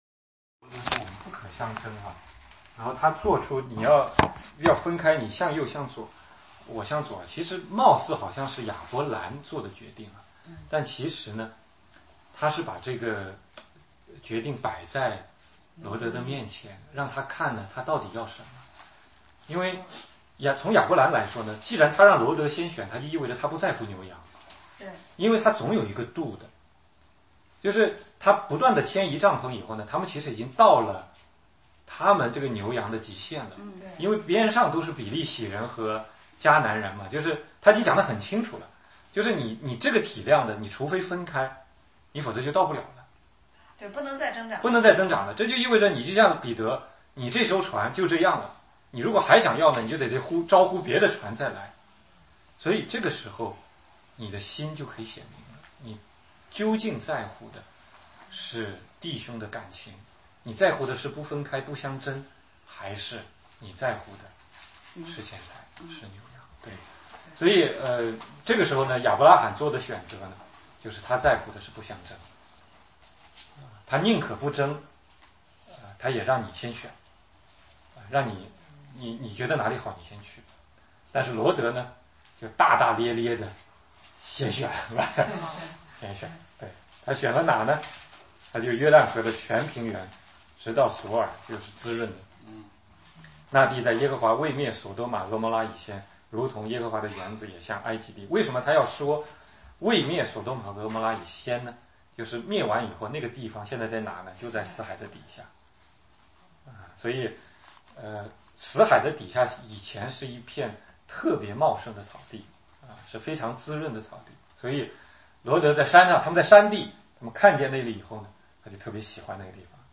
16街讲道录音 - 创世纪13,14